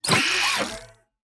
Sensor Interface Button 1.wav